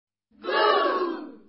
喝倒彩.mp3